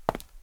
concretFootstep04.wav